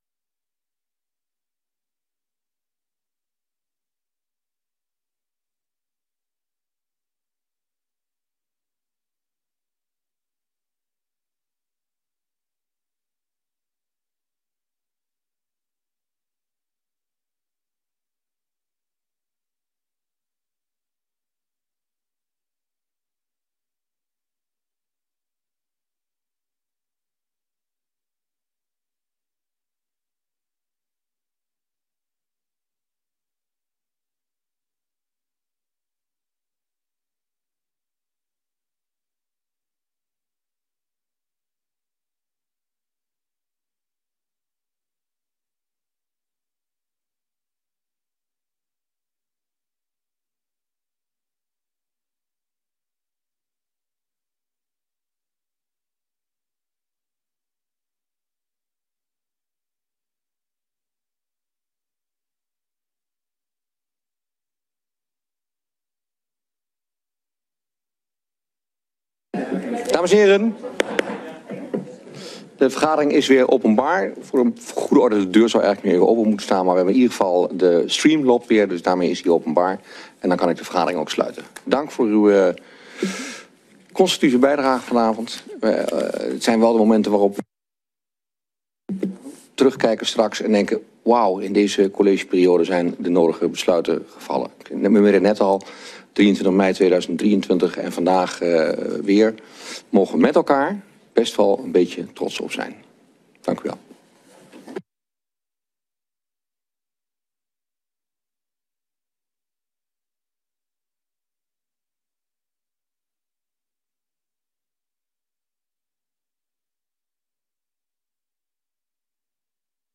Raadsvergadering 08 mei 2025 19:30:00, Gemeente Dronten